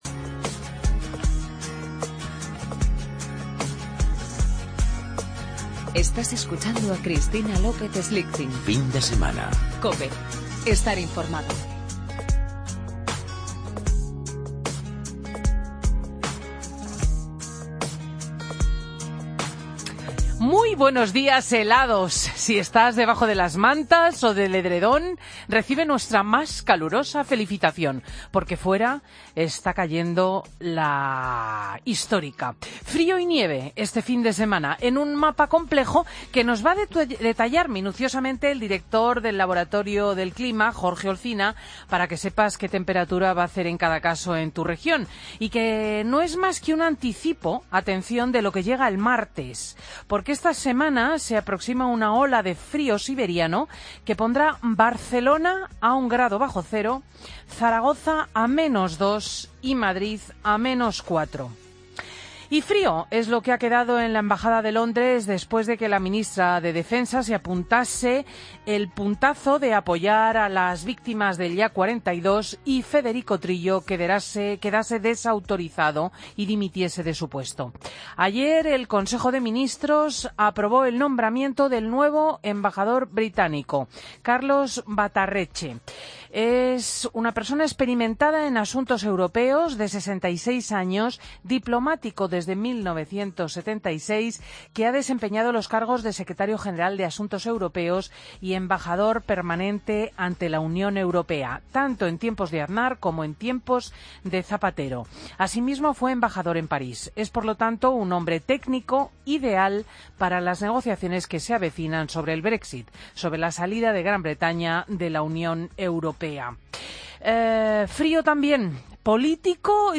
Escucha el editorial de Cristina López Schlichting en 'Fin de semana COPE' del sábado 14 de enero de 2017